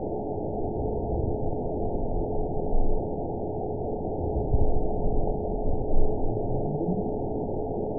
event 919727 date 01/19/24 time 16:22:30 GMT (1 year, 3 months ago) score 9.53 location TSS-AB03 detected by nrw target species NRW annotations +NRW Spectrogram: Frequency (kHz) vs. Time (s) audio not available .wav